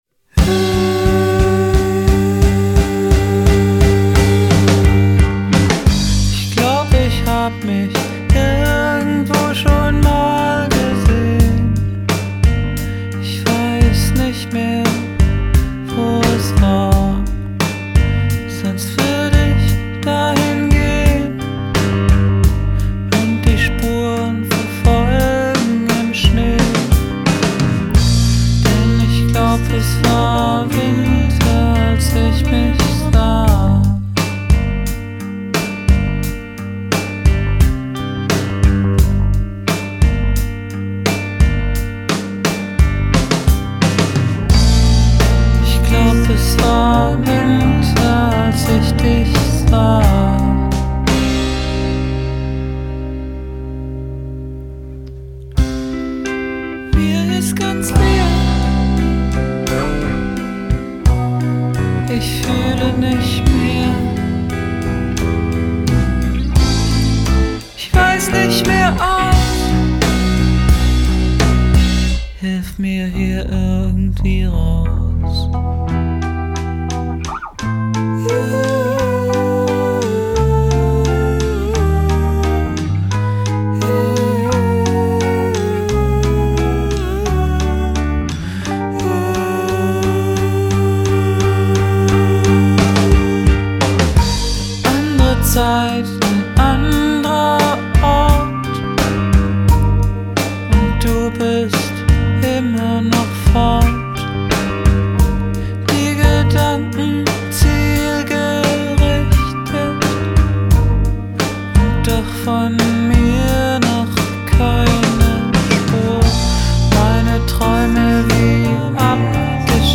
Lichtenberger "Vorproduktion", Oktober 2008